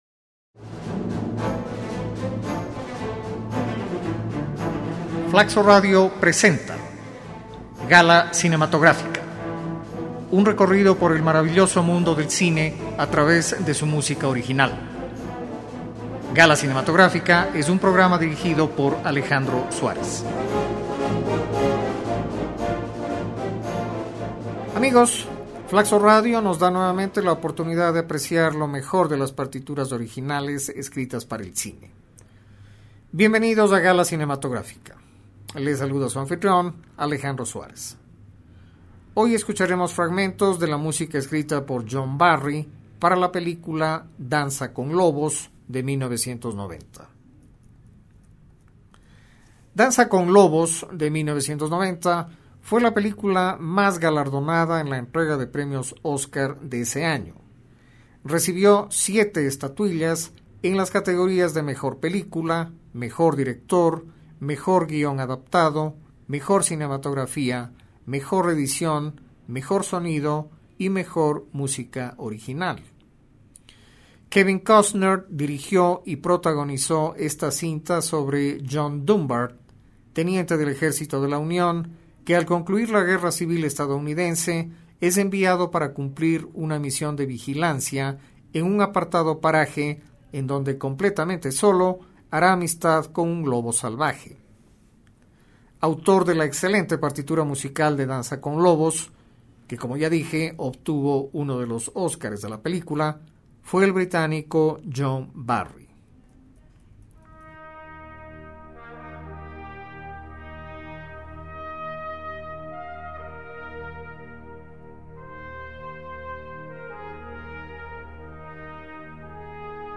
De tono predominantemente sobrio